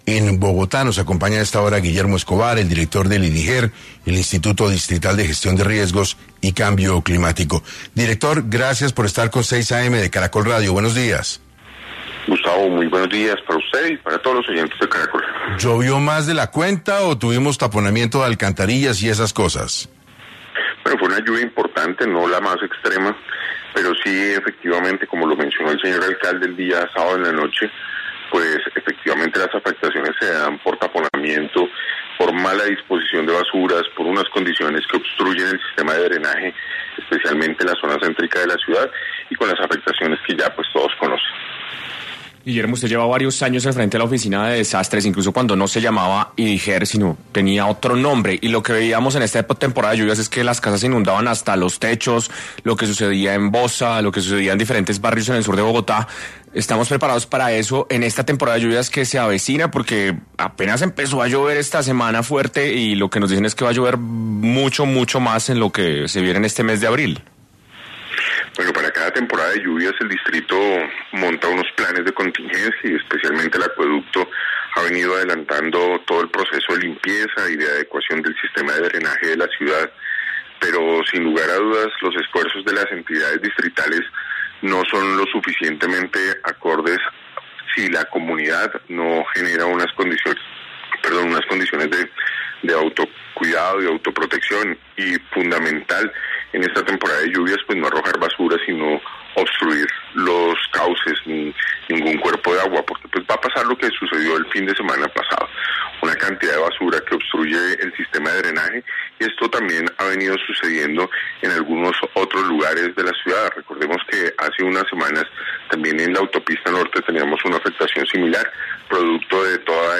En entrevista para 6AM, Guillermo Escobar, director del Instituto Distrital de Gestión de Riesgos y Cambio Climático (Ideger), explicó las razones por la que se origina el problema, expuso los daños que hay hasta el momento y dio consejo a la ciudadanía para saber cómo atender y prevenir estas situaciones.